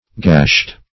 Gash \Gash\ (g[a^]sh), v. t. [imp. & p. p. Gashed (g[a^]sht);